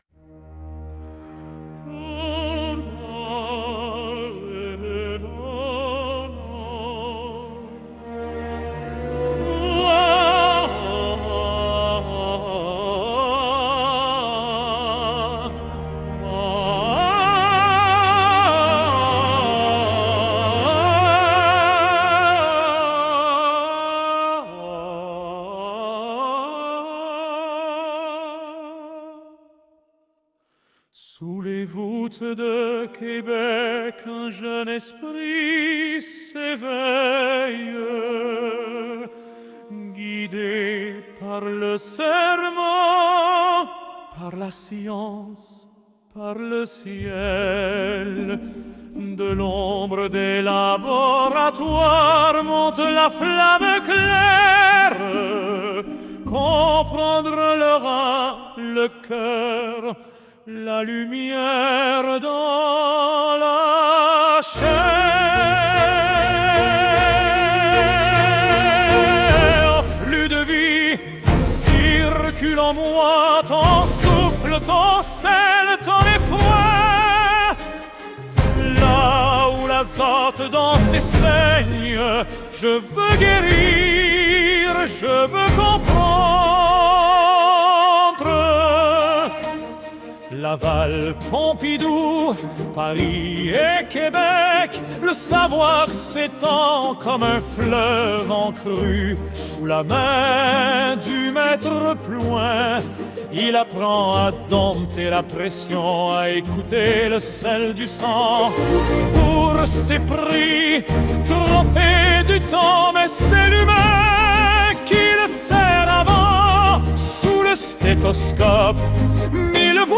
Dans une atmosphère aux accents d’opéra
Cantate pour un coeur sous pression :